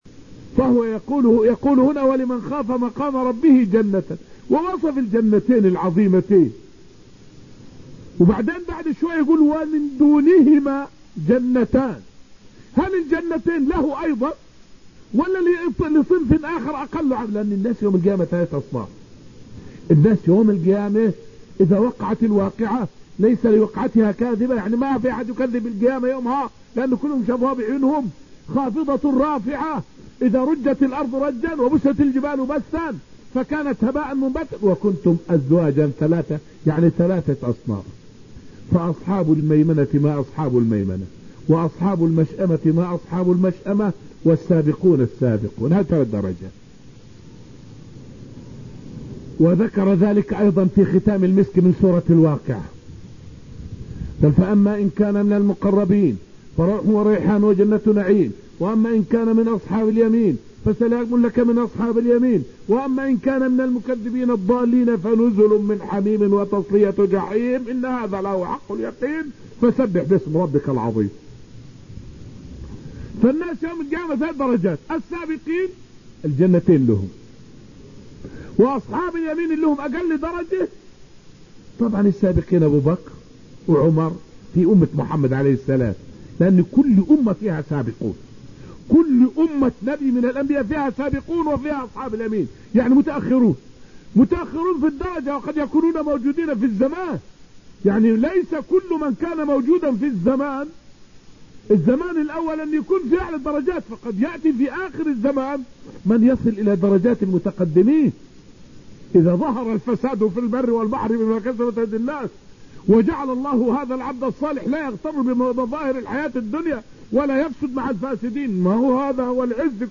فائدة من الدرس الحادي عشر من دروس تفسير سورة الرحمن والتي ألقيت في المسجد النبوي الشريف حول درجات المؤمنون يوم القيامة.